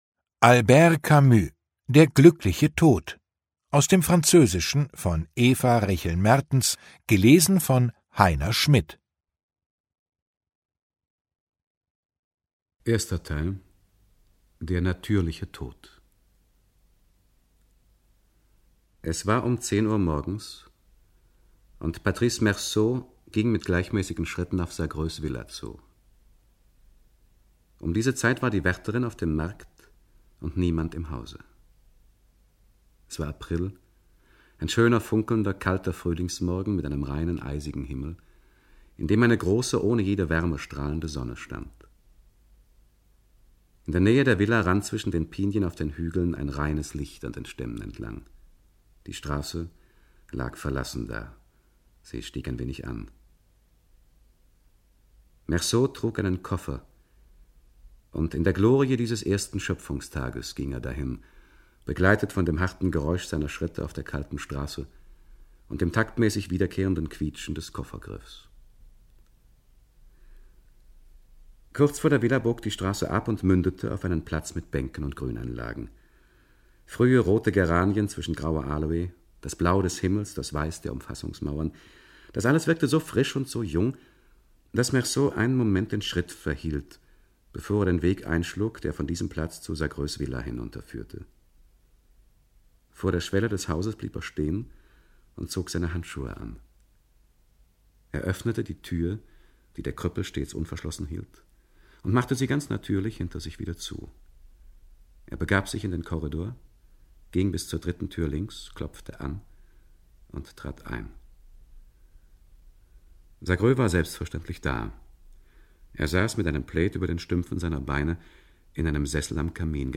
2021 | Ungekürzte Lesung